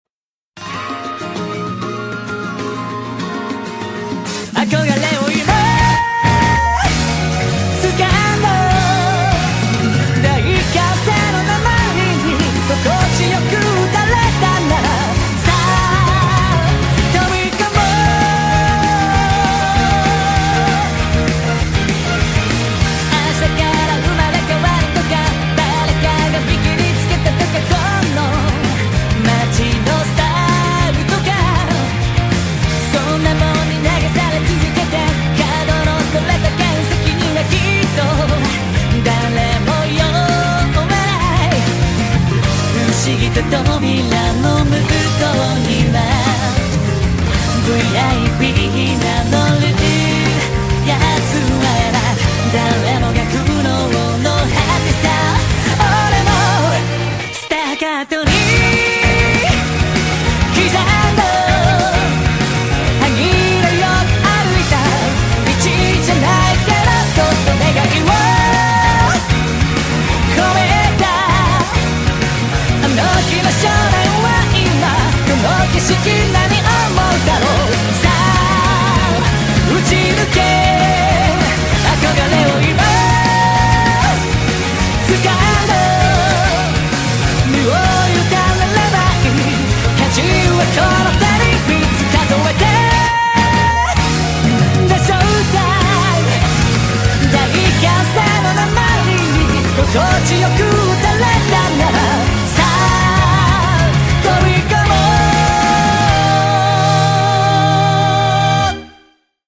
BPM72-210